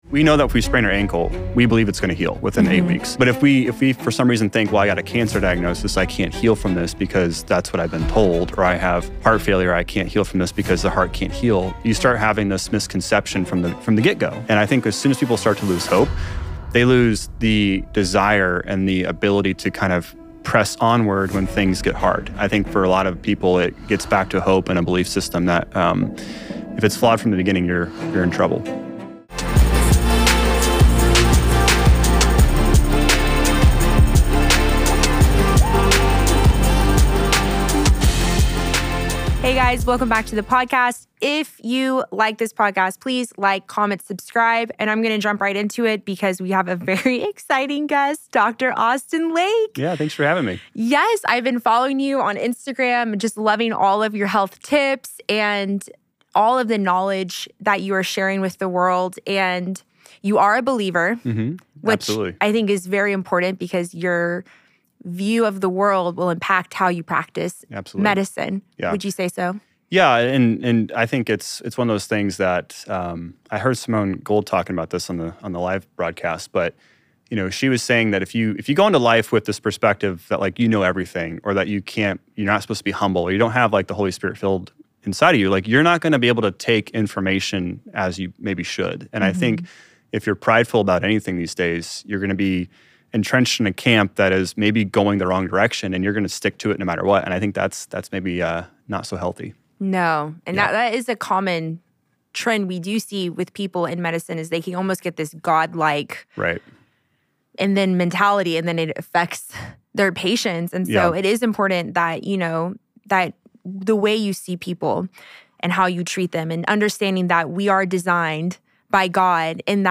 This episode is packed with surprising insights and the kind of honest, faith-based holistic conversation you won’t hear in mainstream health circles.